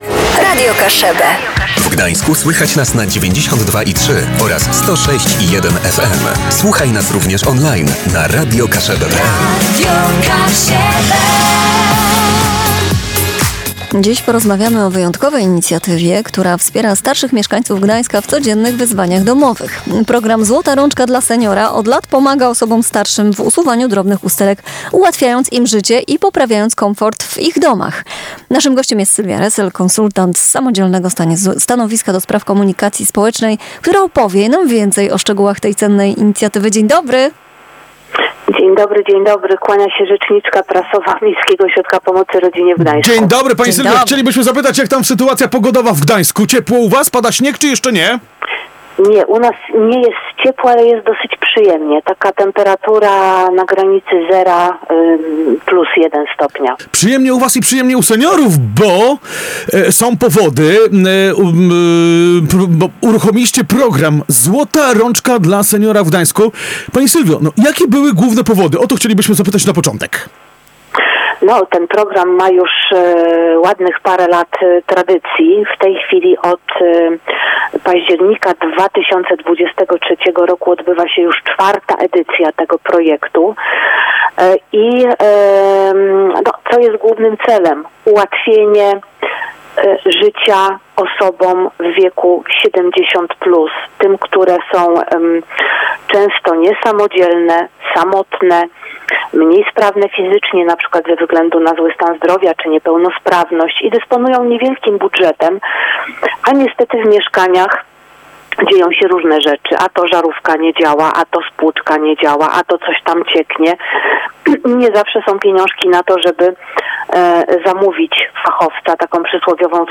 Dziś porozmawiamy o wyjątkowej inicjatywie, która wspiera starszych mieszkańców Gdańska w codziennych wyzwaniach domowych. Program „Złota rączka dla seniora” od lat pomaga osobom starszym w usuwaniu drobnych usterek, ułatwiając im życie i poprawiając komfort w ich domach.